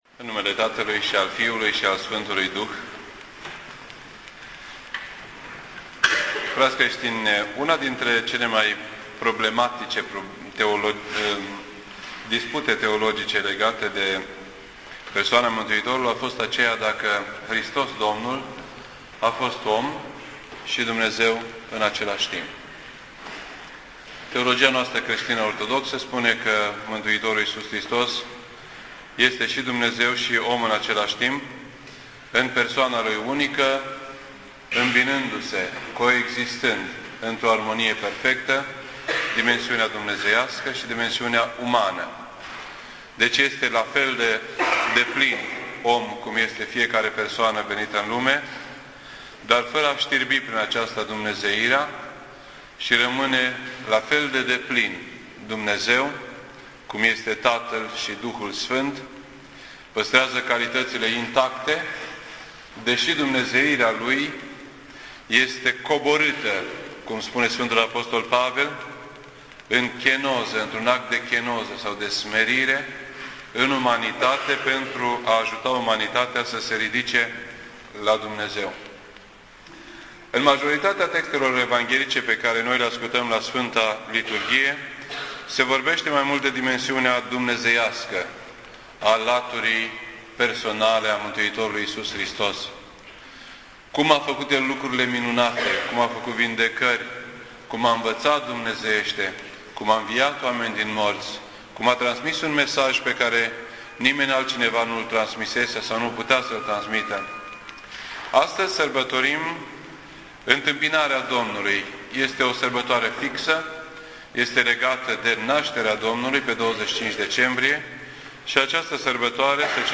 This entry was posted on Tuesday, February 2nd, 2010 at 8:50 PM and is filed under Predici ortodoxe in format audio.